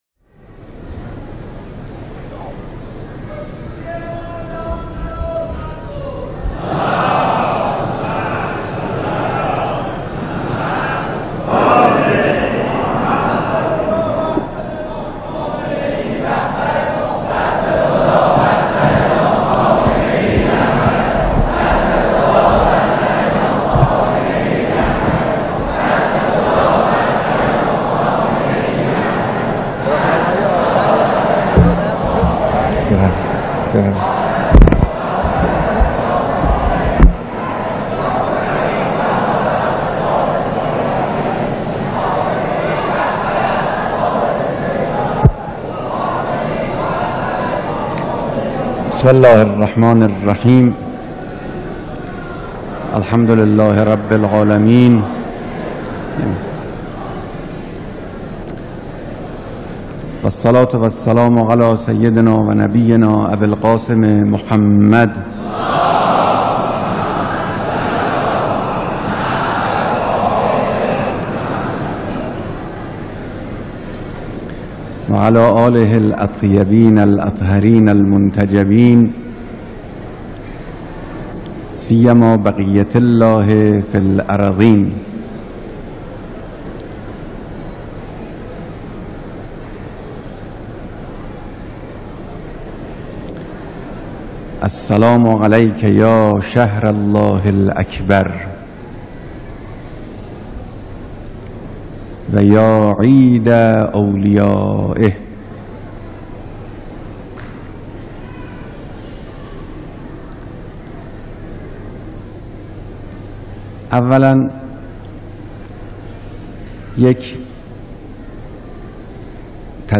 سخنرانی در دیدار با اقشار مختلف مردم (روز سی‌ام ماه مبارک رمضان)